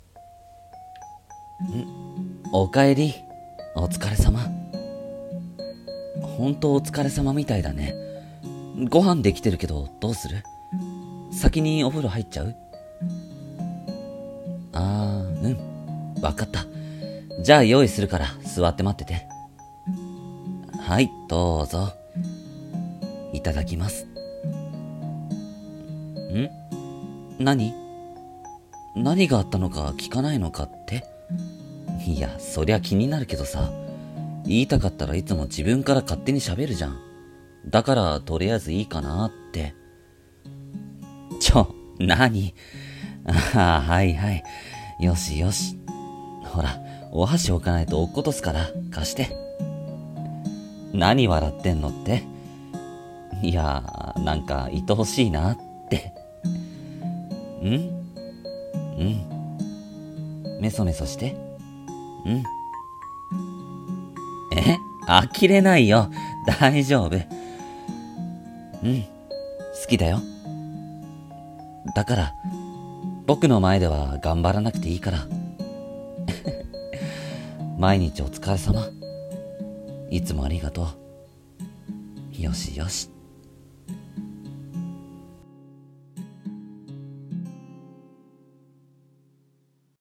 【声劇】一人声劇「君なら大丈夫。」